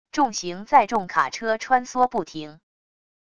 重型载重卡车穿梭不停wav音频